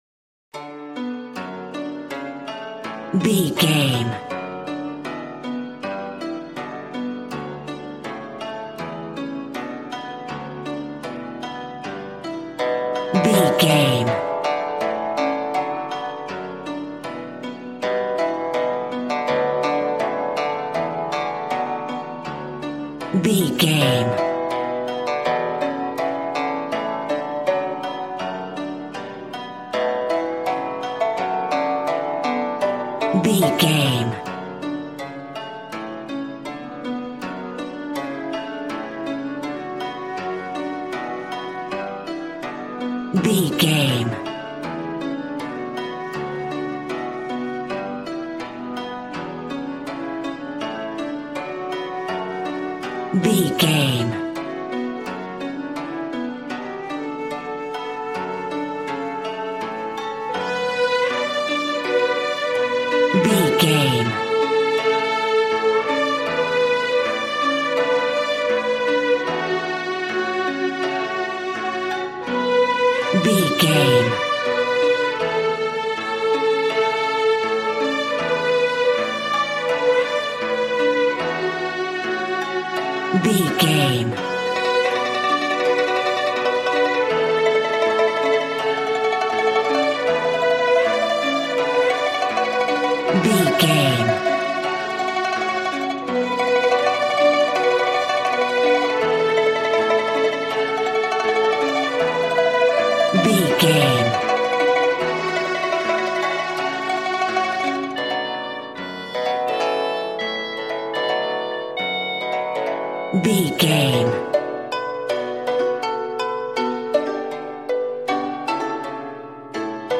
Aeolian/Minor
smooth
conga
drums